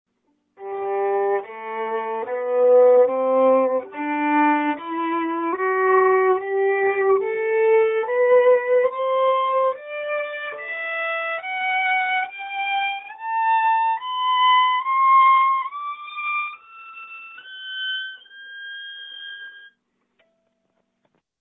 というわけで、簡単なｽｹｰﾙで音色の違いを確認してみました！
3本めの弓(ｸﾞﾗｽﾌｧｲﾊﾞｰ・中国製)
1本めと3本めは響きのﾚﾍﾞﾙは同じくらい。2本めが一番響きますね？